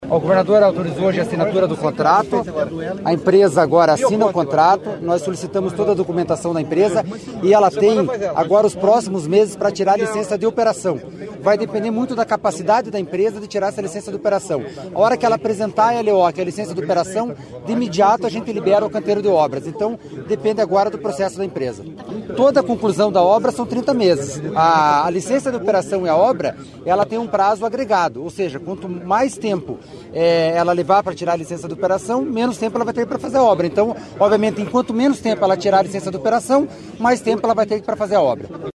Sonora do diretor-presidente da Amep, Gilson Santos, sobre a autorização das obras do novo Contorno Sul de Curitiba